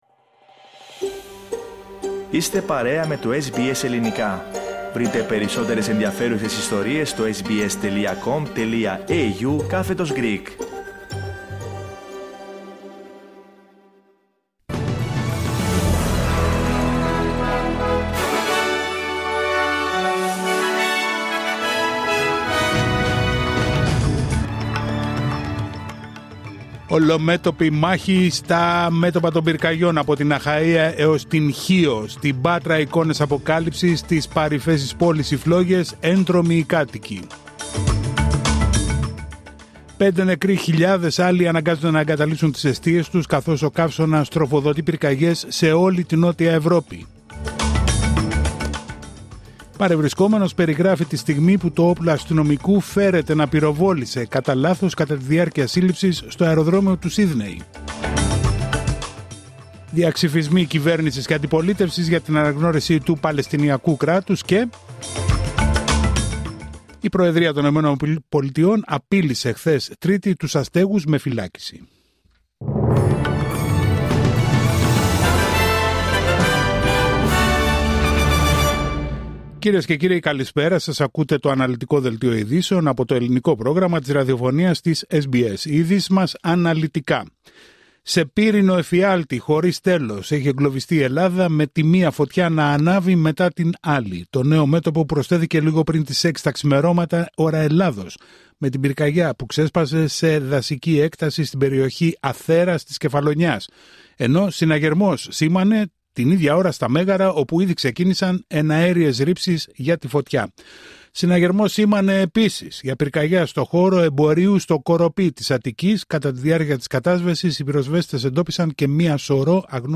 Δελτίο ειδήσεων Τετάρτη 13 Αυγούστου 2025